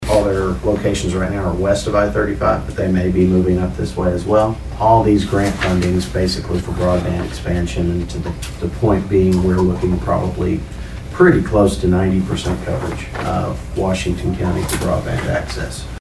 Commissioner Mitch Antle gave more detail on Resound Networks and